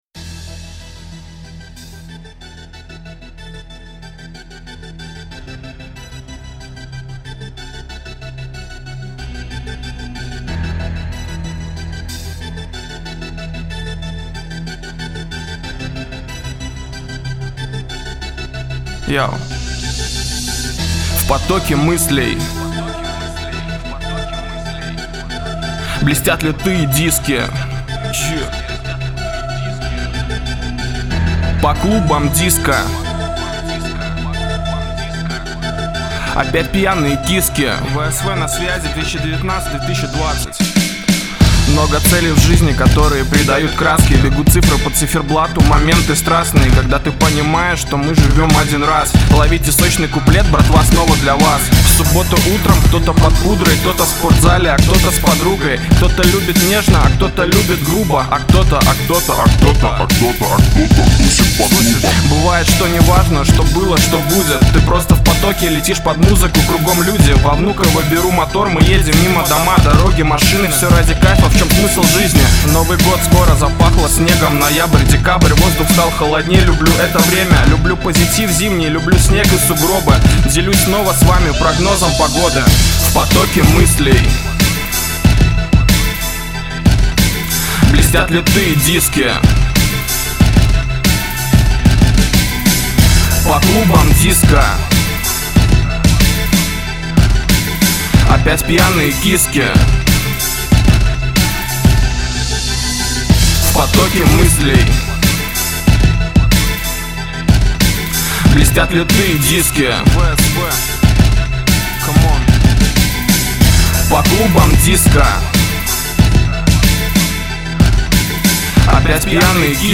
Синглы: Рэп